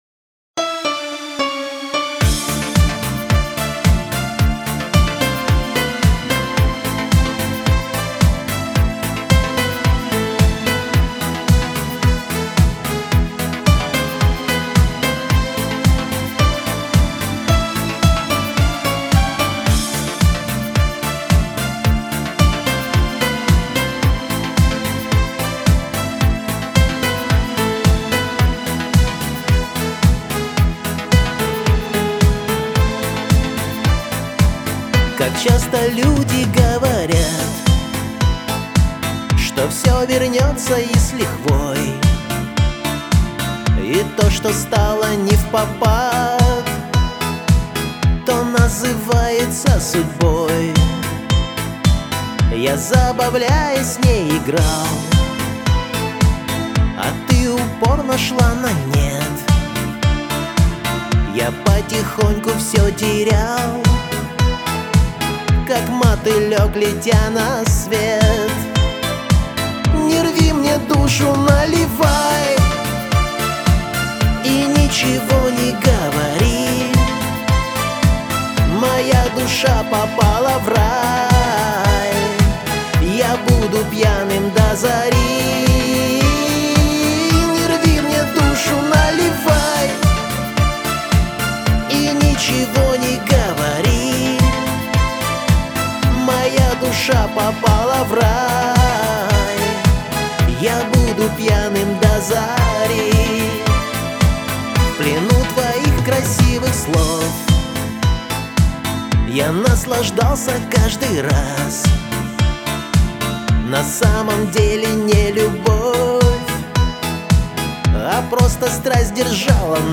Всі мінусовки жанру Disco
Плюсовий запис